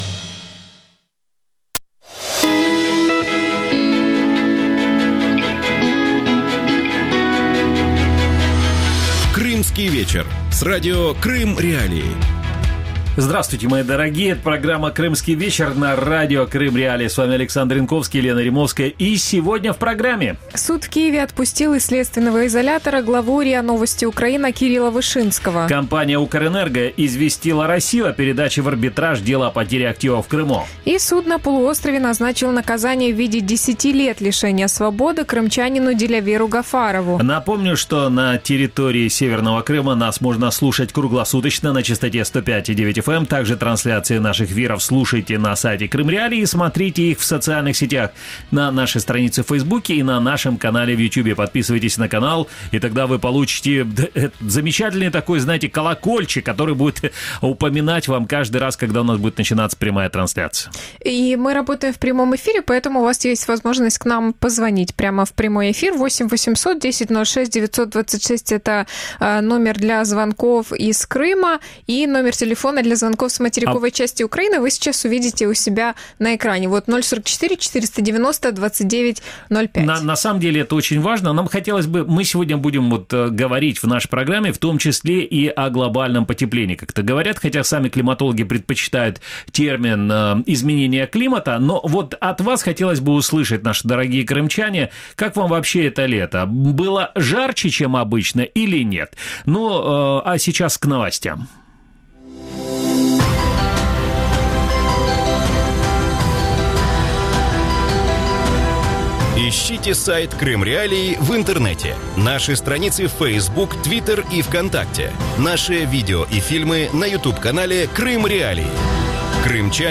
Почему в Крыму с обилием солнечных дней после аннексии не развивается солнечная энергетика? Гости эфира: